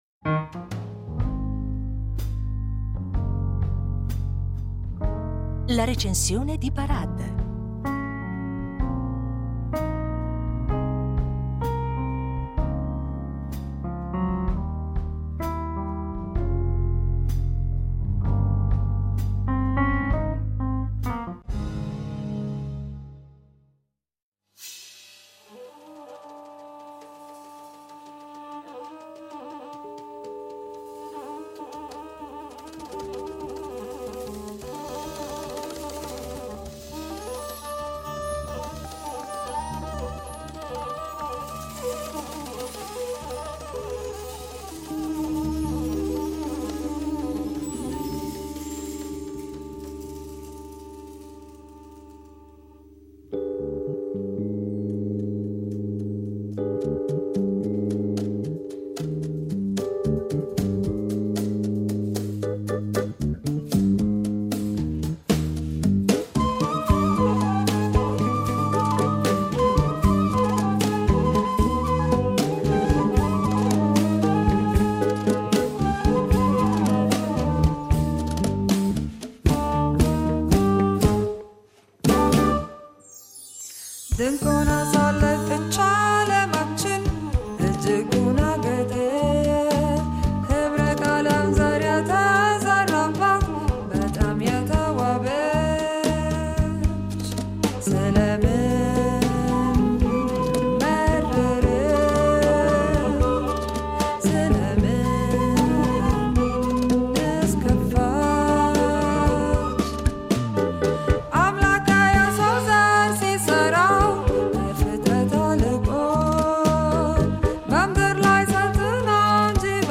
La Recensione Ethio-Gnawa.